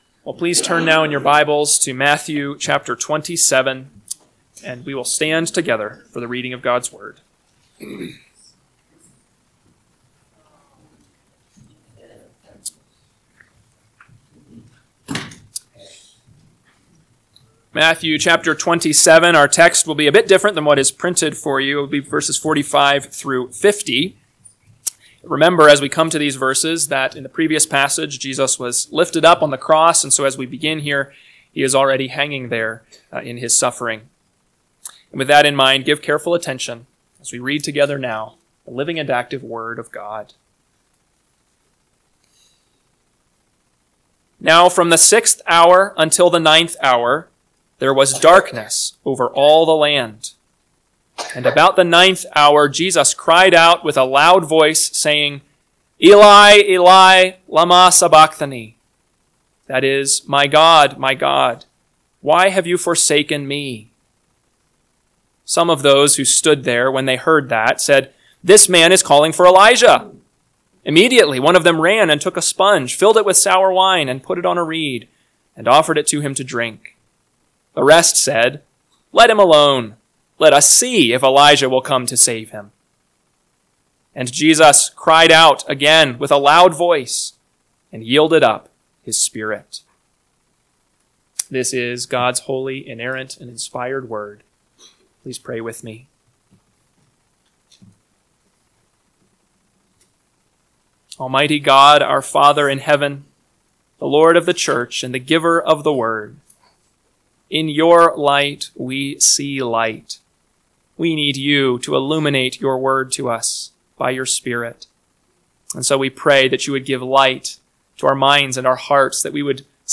AM Sermon – 5/4/2025 – Matthew 27:45-50 – Northwoods Sermons